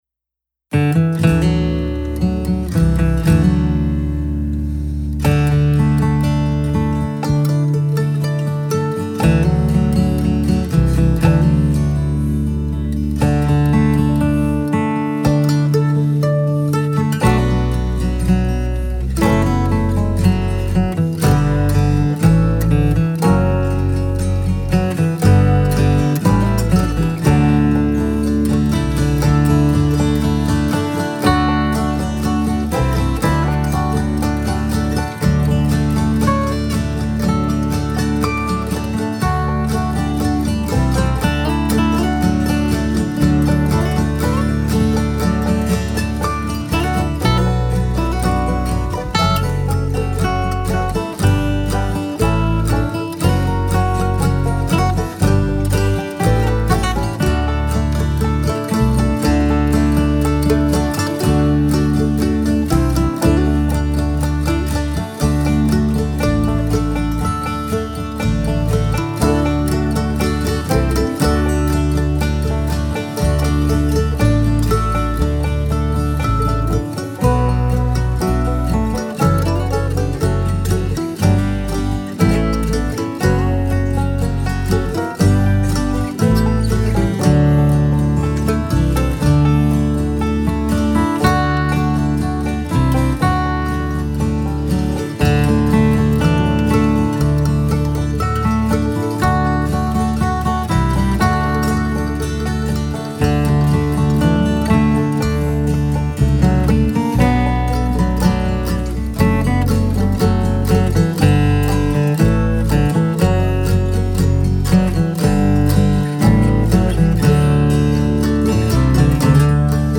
The acoustic instruments all sound so rich and pleasant. It may be a tad boomy, but that's really nitpicking.
There was one guitar that hung out past everything else.
It is a little boomy, I think too.
You certainly know your way around recording acoustic...Great take